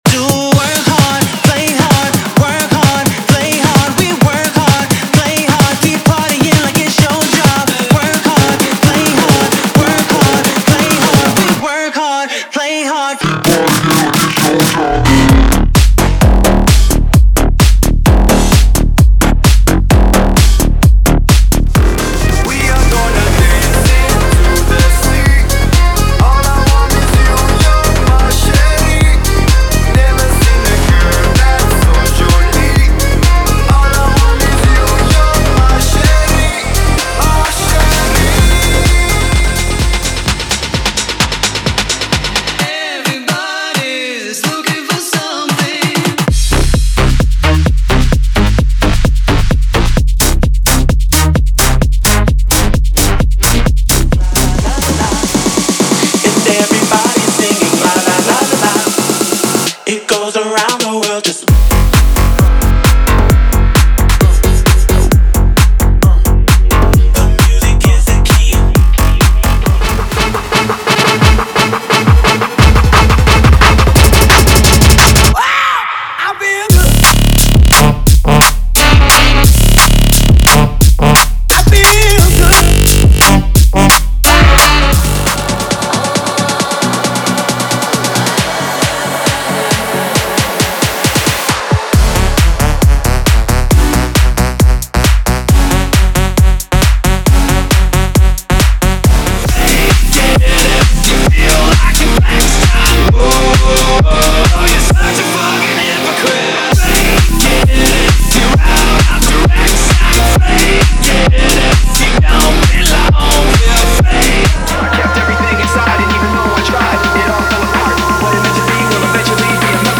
🎶 Ouça as prévias do Pack Eletrônica
100 músicas eletrônicas atualizadas
✅ Músicas sem vinhetas